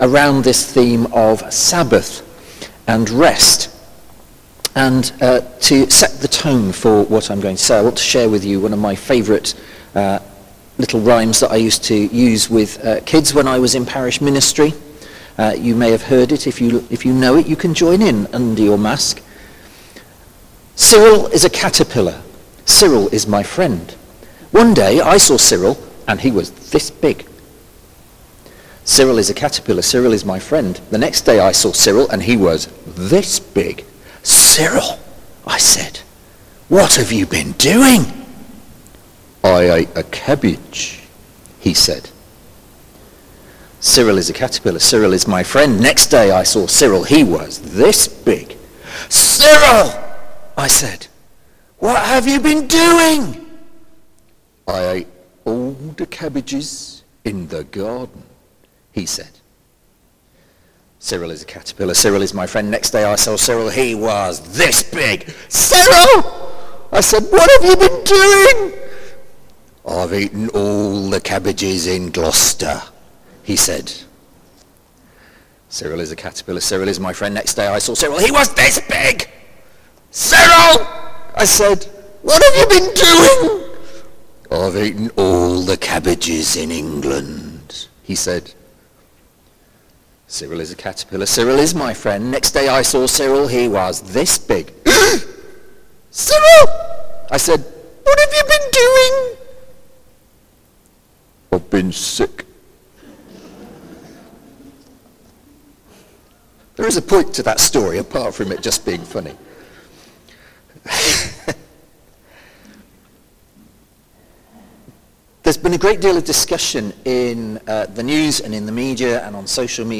Sermon: Sabbath Rest | St Paul + St Stephen Gloucester